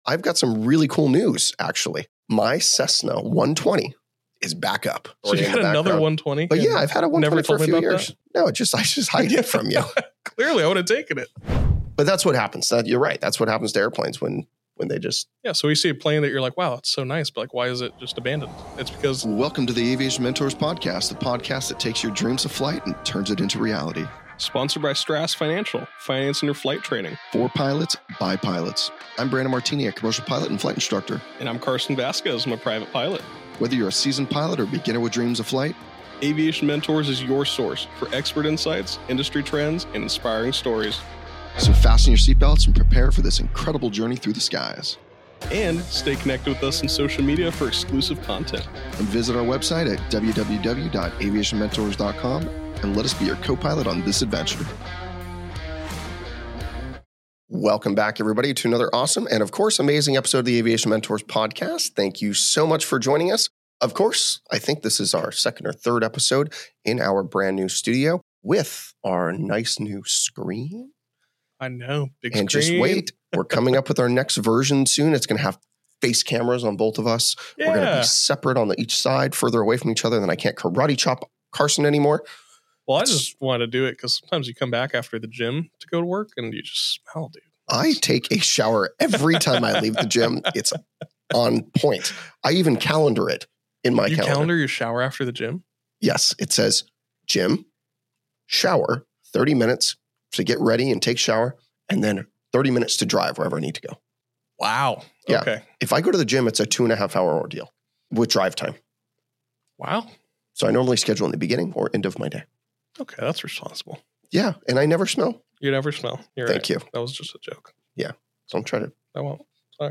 The hosts also discuss risk tolerance, flight readiness, and decision-making when returning to flying after time away. Packed with humor, personal insight, and practical advice, this episode is a must-listen for any pilot thinking about owning, maintaining, or reviving their own aircraft.